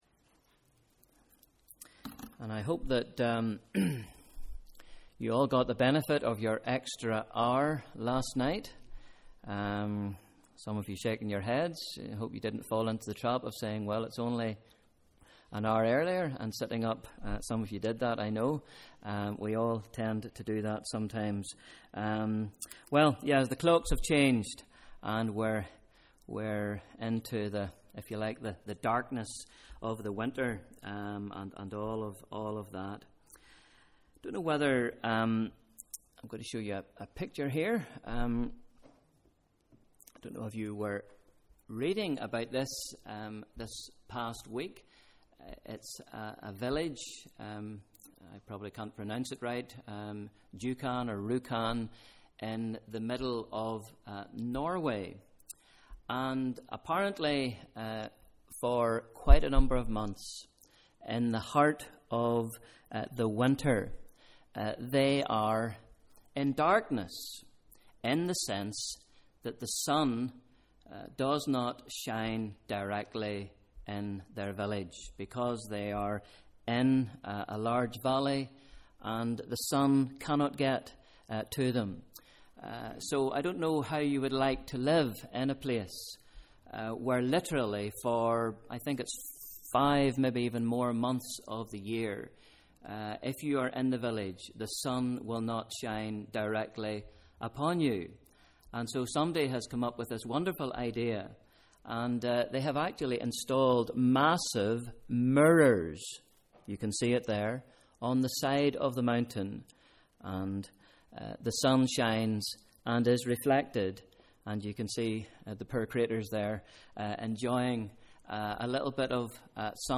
Bible Reading: Matthew 4 v 12-17; 5 v 14-16 Evening Service: Sunday 27th October